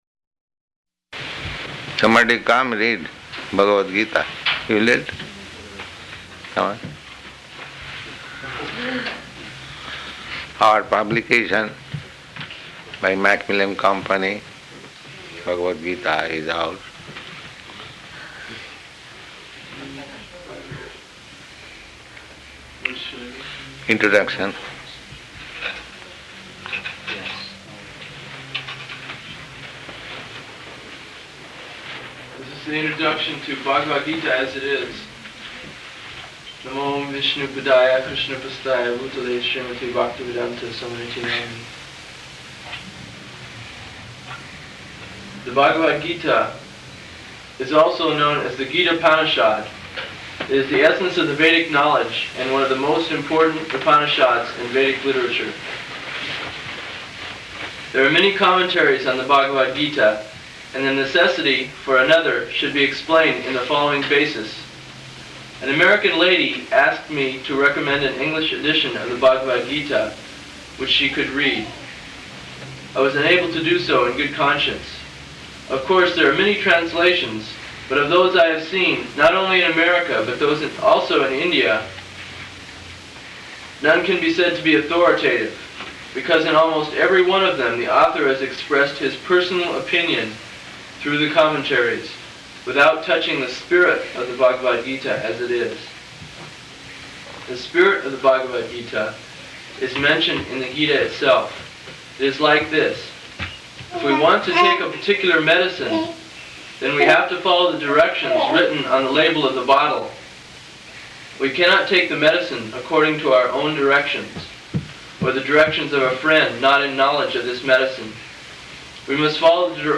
Introduction to Bhagavad-gītā As It Is --:-- --:-- Type: Bhagavad-gita Dated: November 23rd 1968 Location: Los Angeles Audio file: 681123BG-LOS_ANGELES.mp3 Prabhupāda: Somebody come read Bhagavad-gītā .